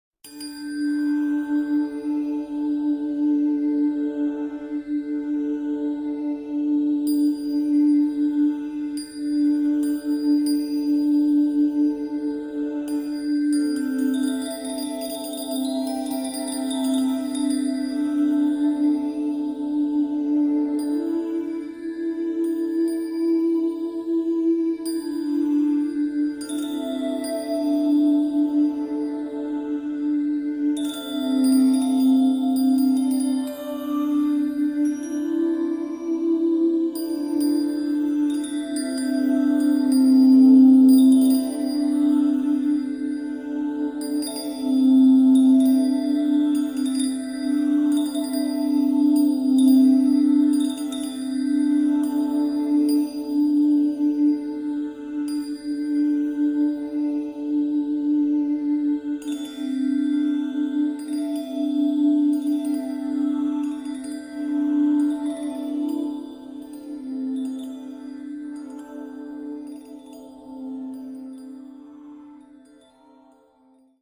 The music you will be hearing is the improvised